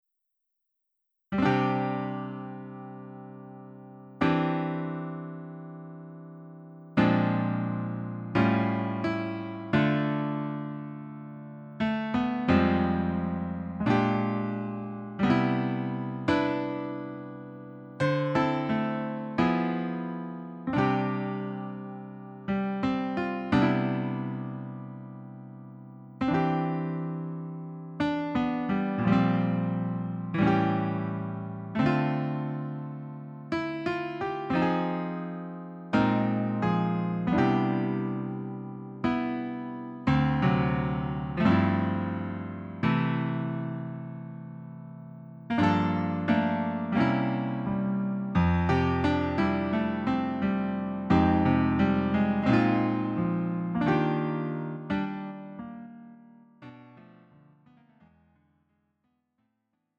음정 원키 3:44
장르 가요 구분 Lite MR